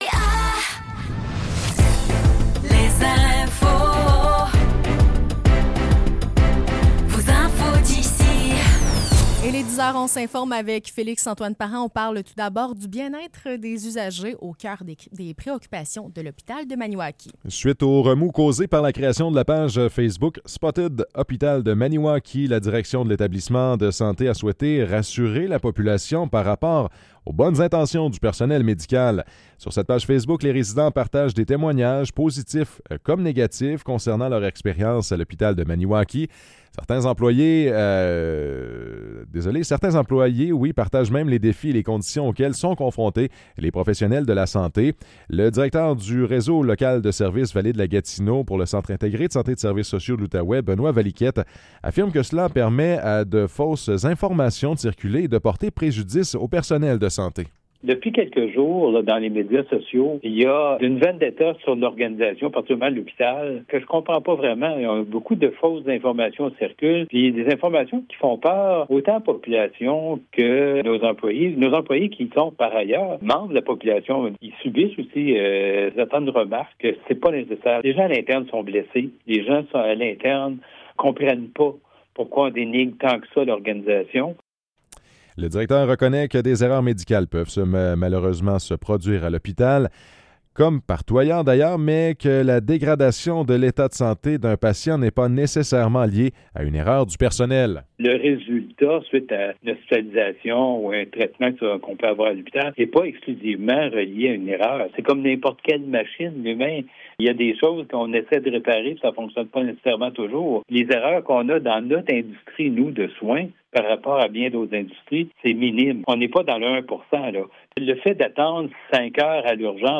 Nouvelles locales - 10 janvier 2025 - 10 h